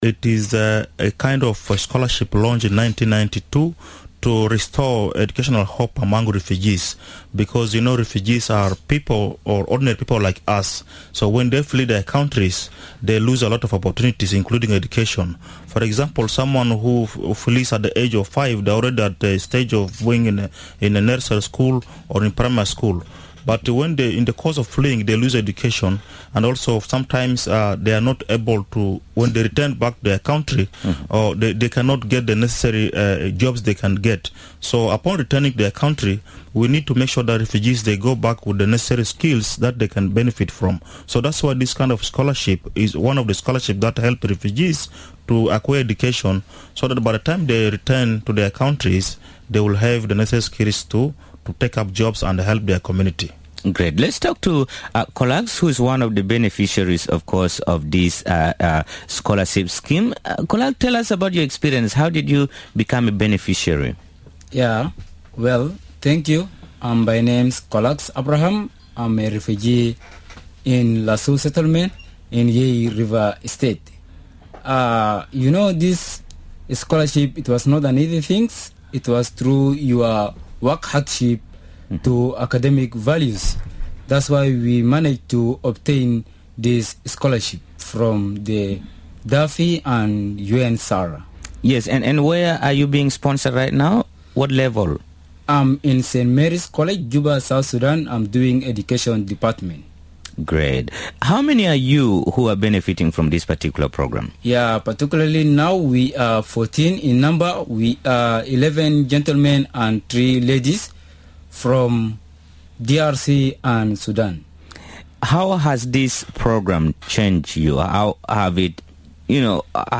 one of the beneficiaries studying at St Mary’s College in Juba visited Radio Miraya studio's to tell us more about this scholarship program and it's impact on the refugee students.